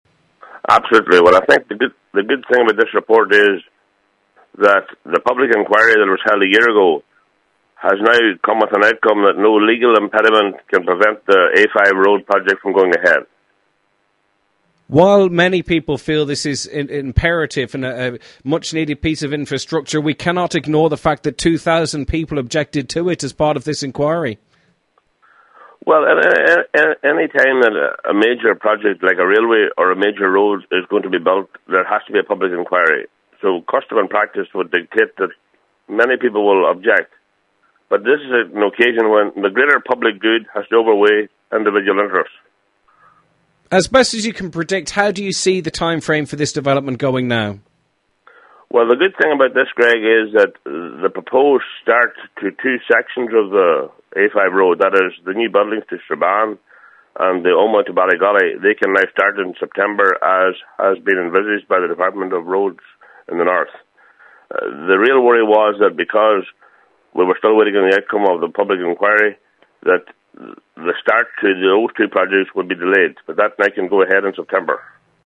West Tyrone MLA Joe Byrne has welcomed the news – he says despite the objections, it was important that the road went ahead for the greater good: